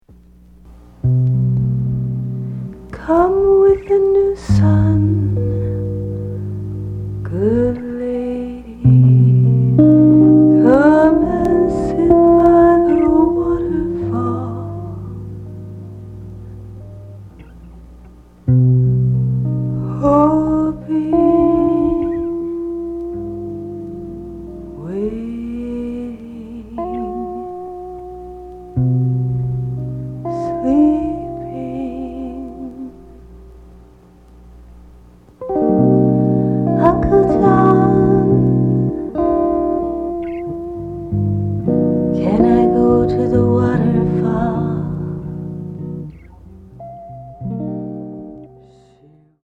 じっとりと湿った夜のメランコリー。
即興　フォーク